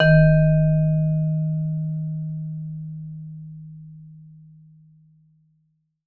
Index of /90_sSampleCDs/Sampleheads - Dave Samuels Marimba & Vibes/VIBE CMB 2C